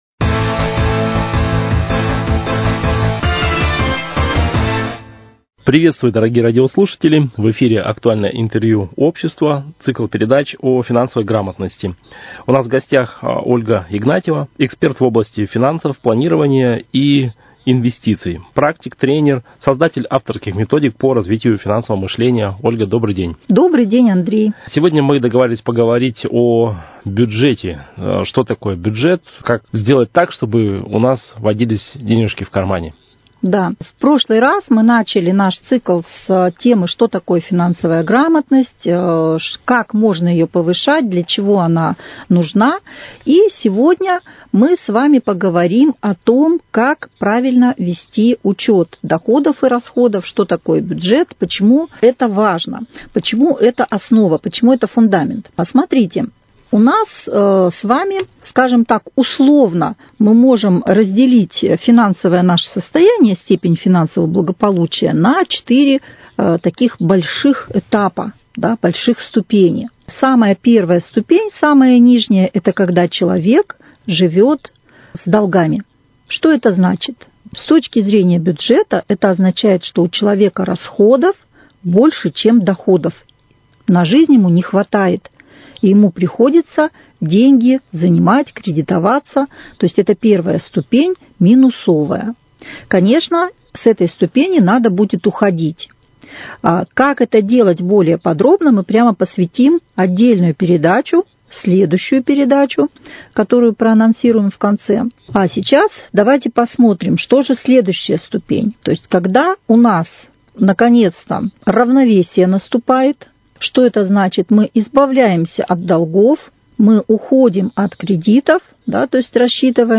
Актуальное интервью